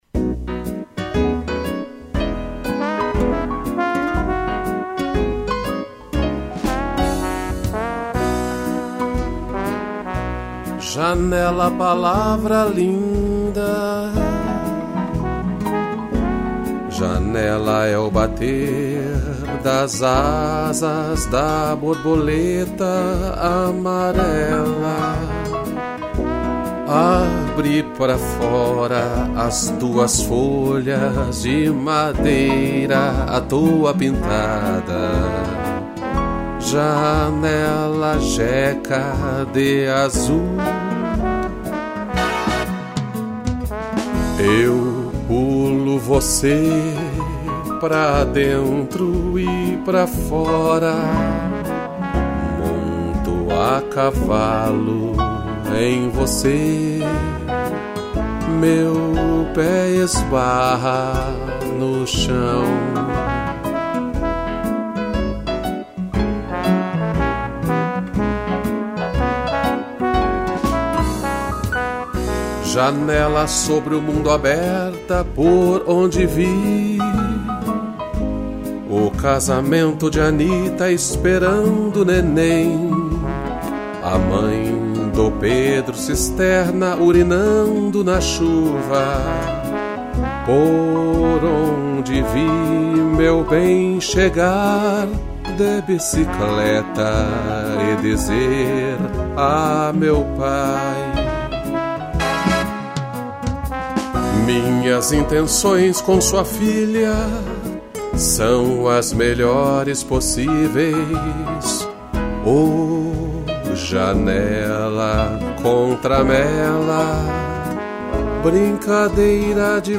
voz e violão
piano e trombone